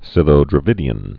(sĭthō-drə-vĭdē-ən, sĭth-)